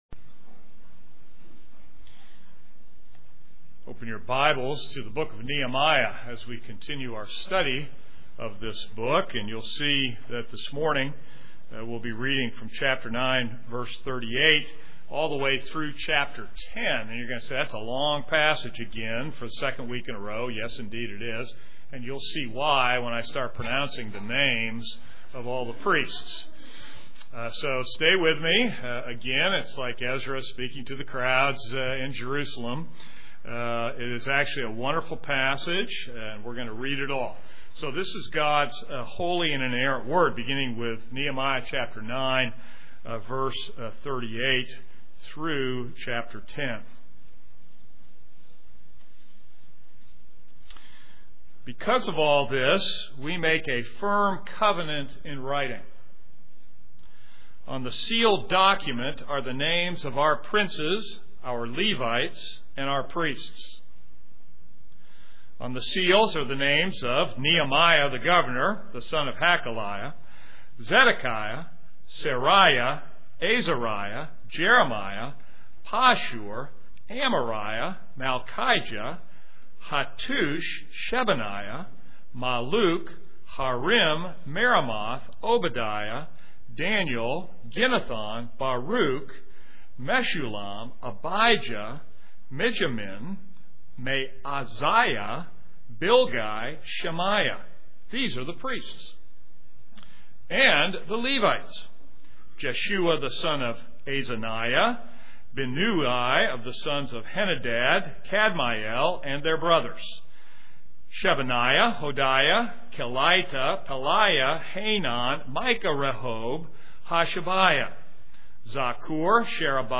This is a sermon on Nehemiah 9:38-10:39.